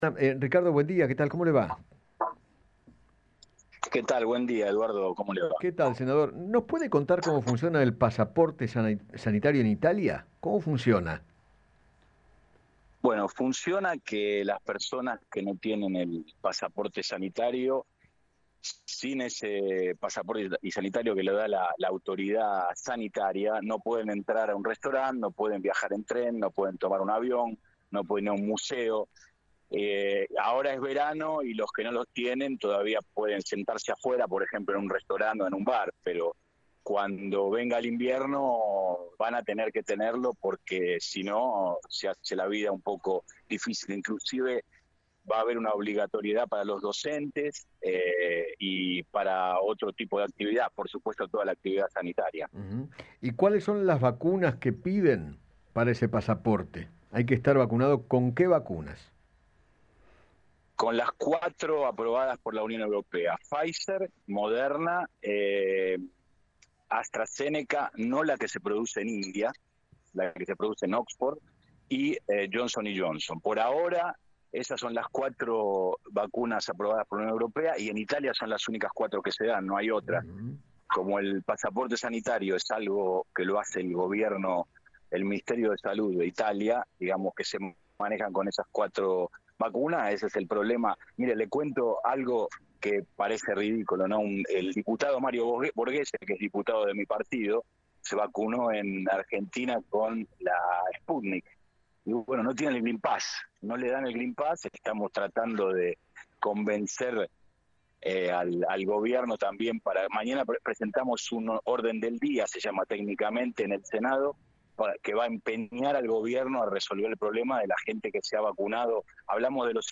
Ricardo Merlo, senador de la República de Italia, dialogó con Eduardo Feinmann sobre dicho pasaporte y aseguró que para ingresar al país “hay que estar vacunado con alguna de las 4 vacunas aprobadas: Pfizer, Moderna, Johnson & Johnson y AstraZeneca”.